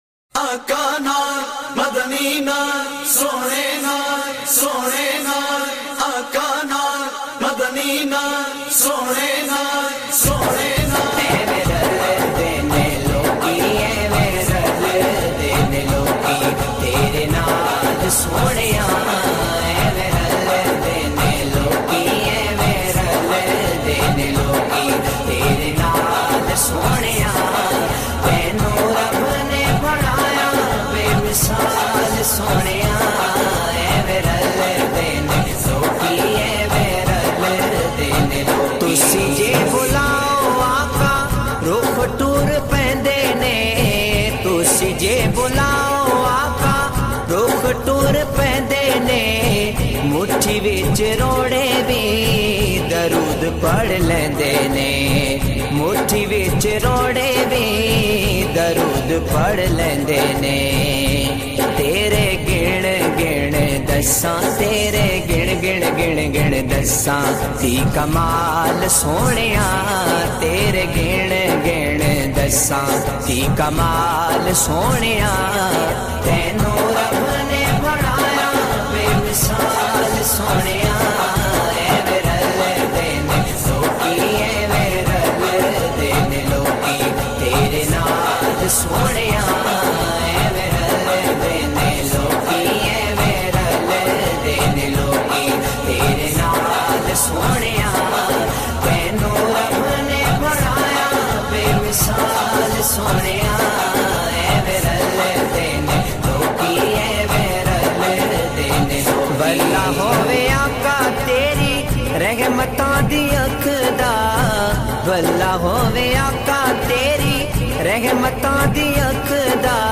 He got fame with his melodious voice and unique style.